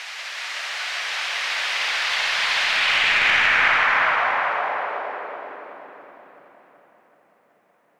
描述：带延迟和混响的滑音效果，120 bpm
Tag: 120 bpm Weird Loops Fx Loops 1.35 MB wav Key : Unknown